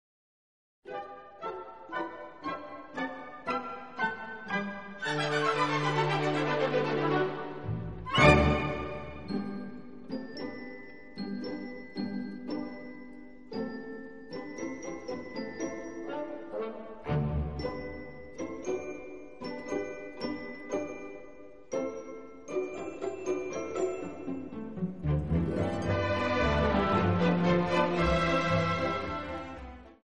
Major Scale